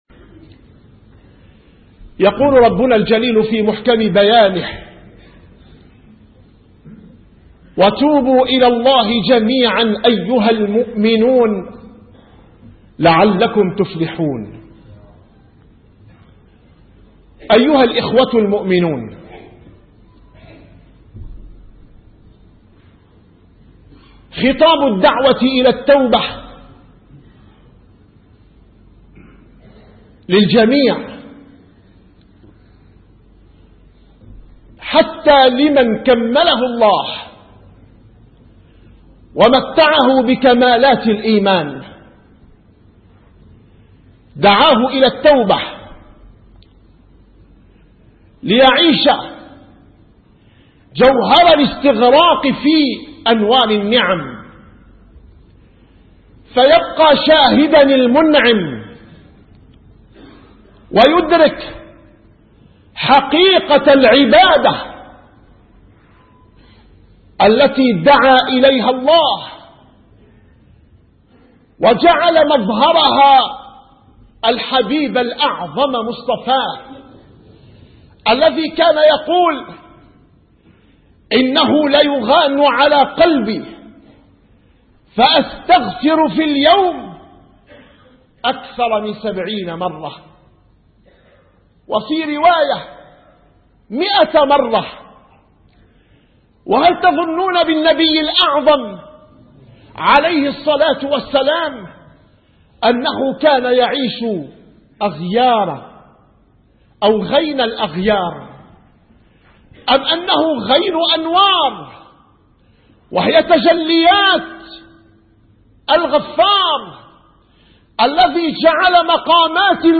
- الخطب - خطبة